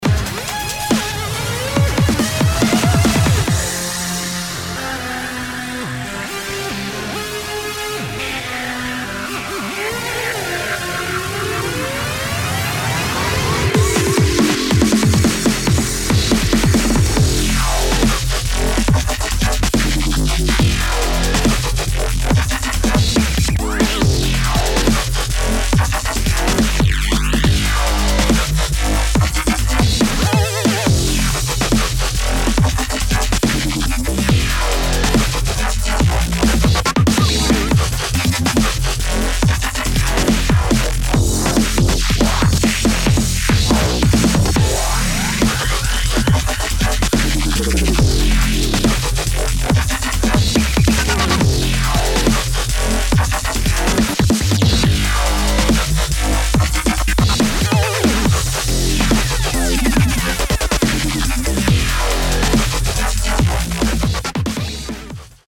[ DUBSTEP / TRAP / GRIME ]